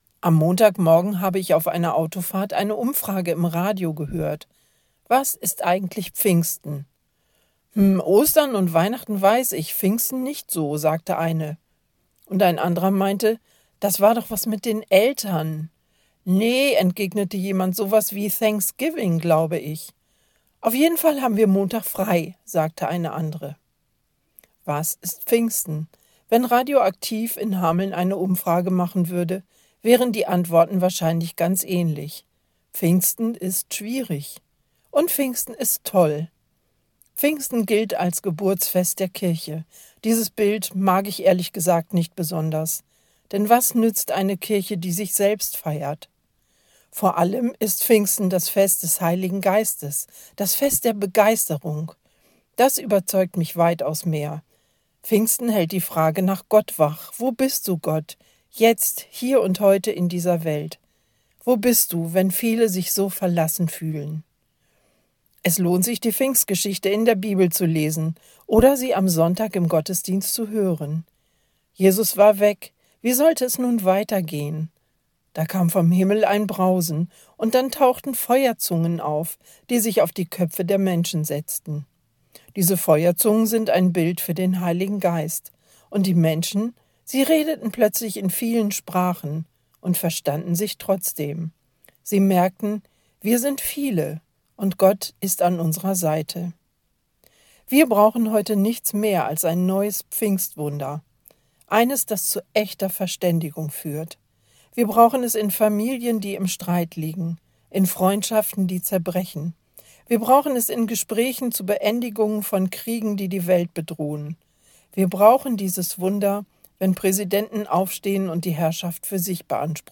Radioandacht vom 5. Juni